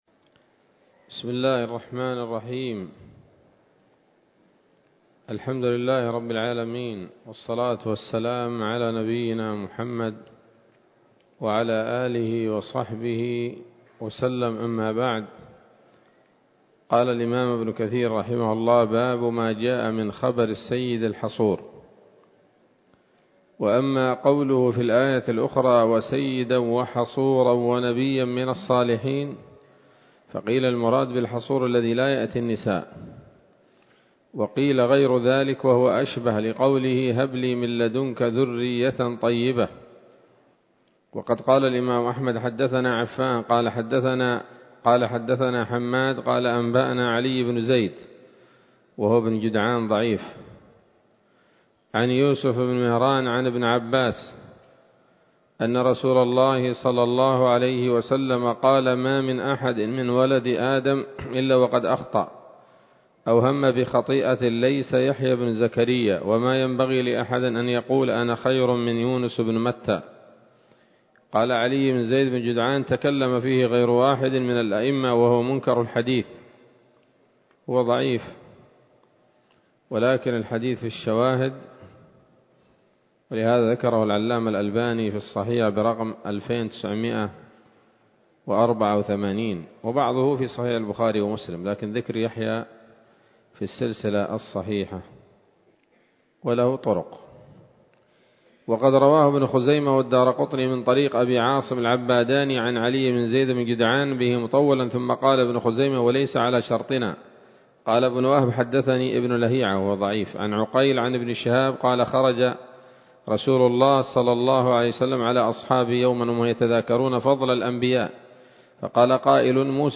‌‌الدرس الرابع والثلاثون بعد المائة من قصص الأنبياء لابن كثير رحمه الله تعالى